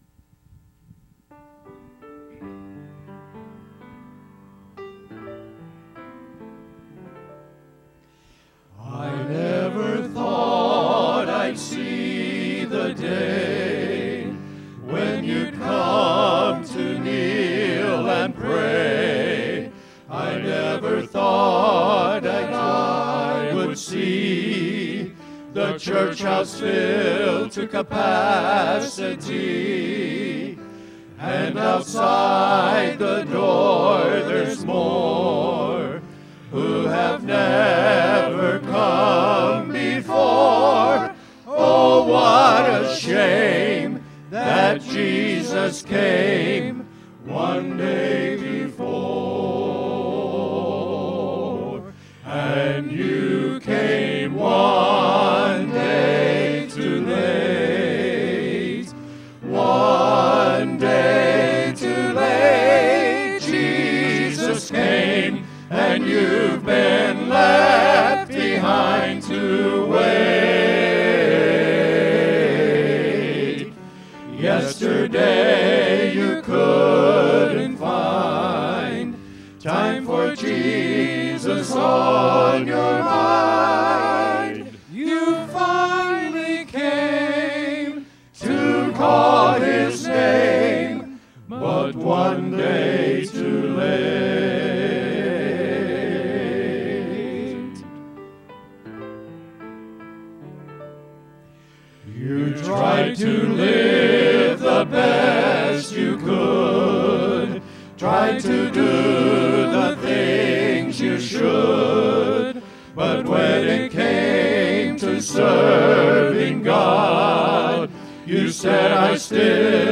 Our Men’s Quartet added an extra voice last Sunday night and sung a very powerful song.
– Men’s Quartet + 1 – Faith Baptist Church
one-day-too-late-mens-quartet-1-fbc.mp3